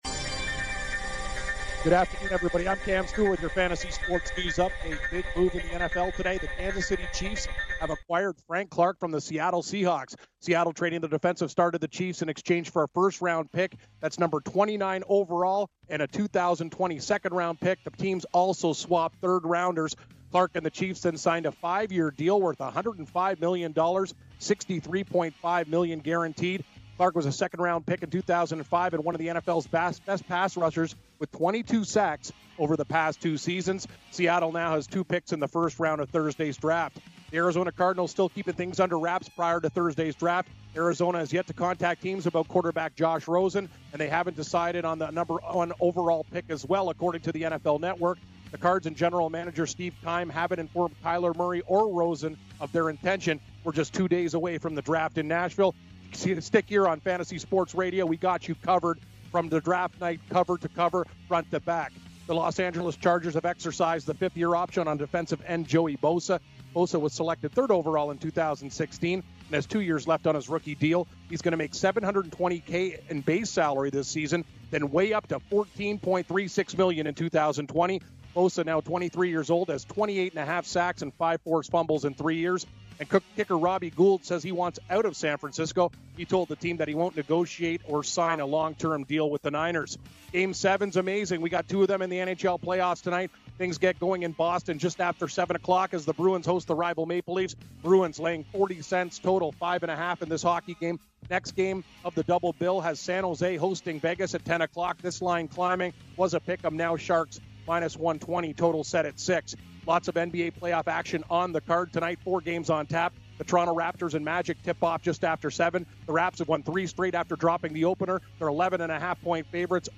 NHL Game 7's, MLB DFS Preview, NBA Playoff Preview, Re-Air of Jeremy Roenick Interview